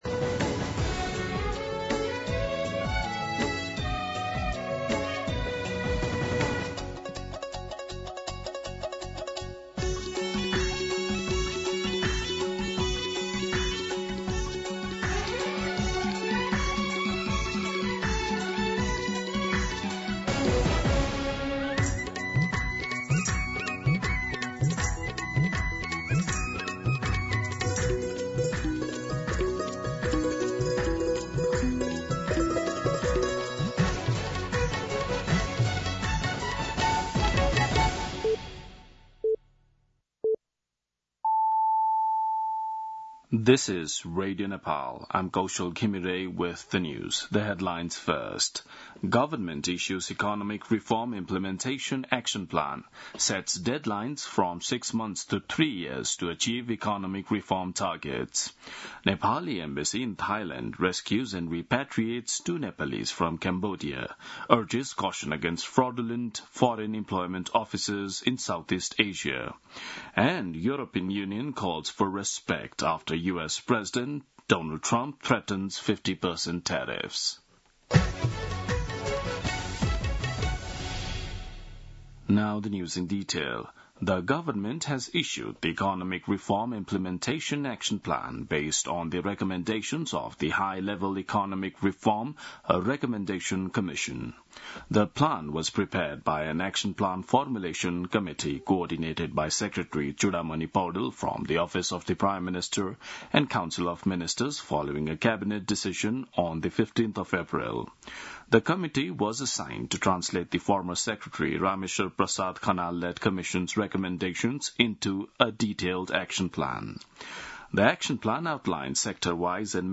दिउँसो २ बजेको अङ्ग्रेजी समाचार : १० जेठ , २०८२
2-pm-English-News-4.mp3